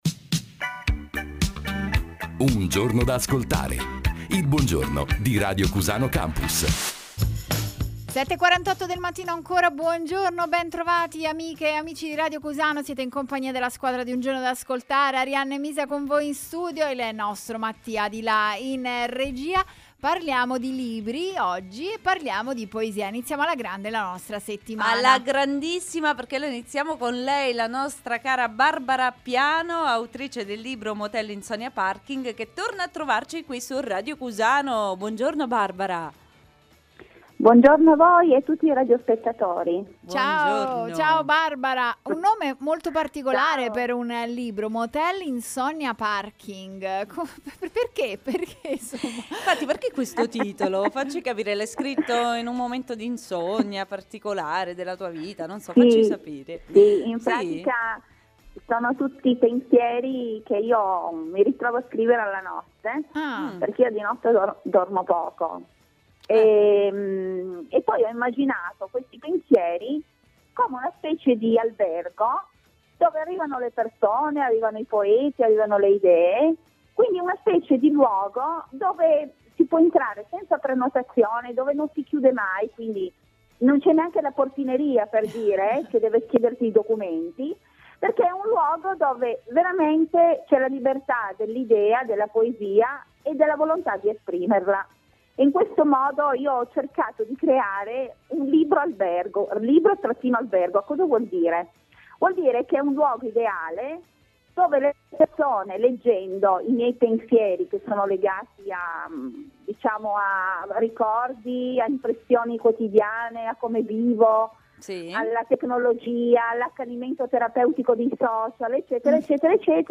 Intervista a Radio Cusano Campus del 10 febbraio 2020